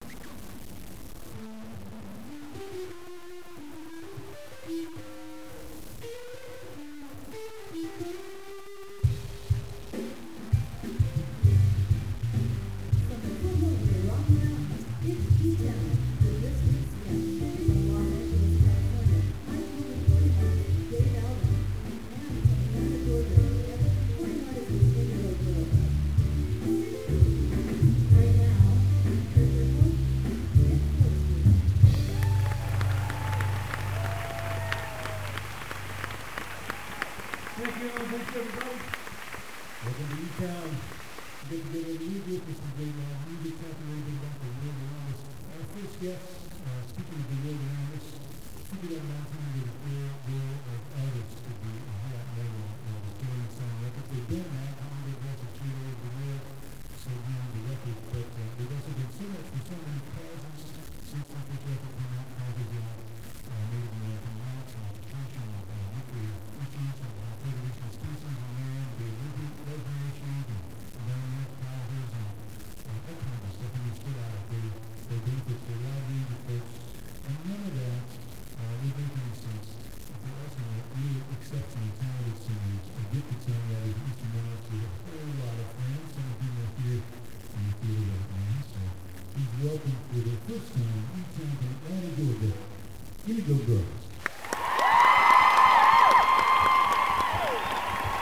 01. introduction (1:38)